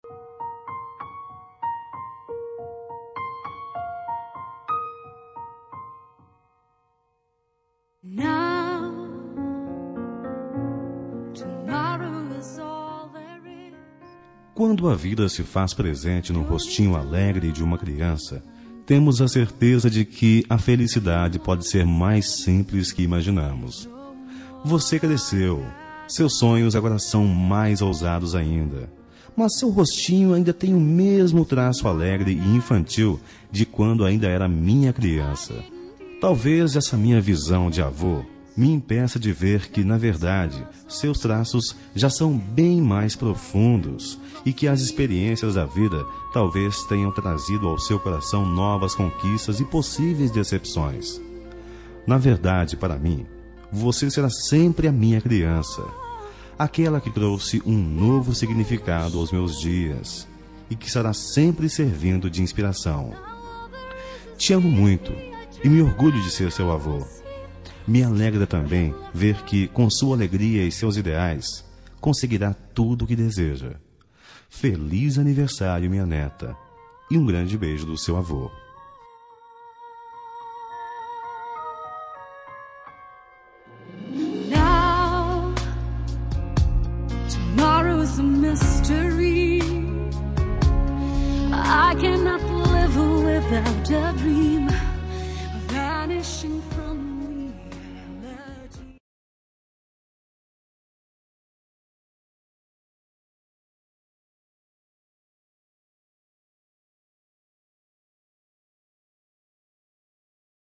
Aniversário de Neta – Voz Masculina – Cód: 131037